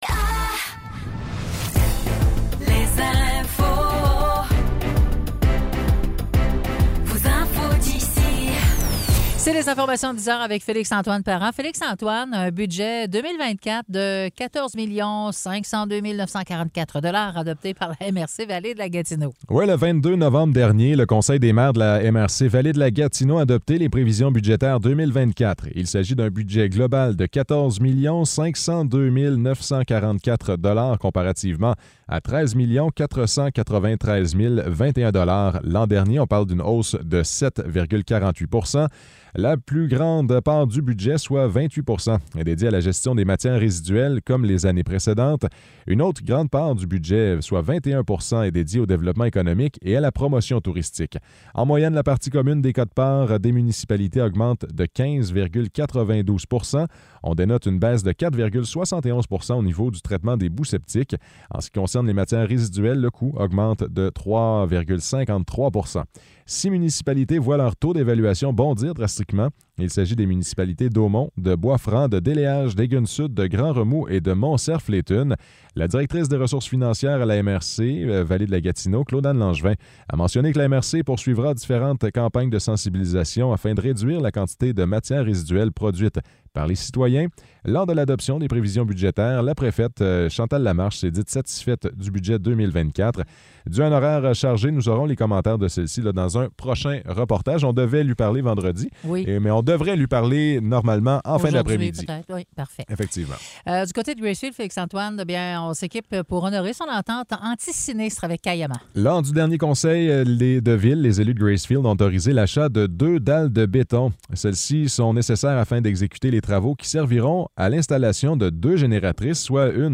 Nouvelles locales - 27 novembre 2023 - 10 h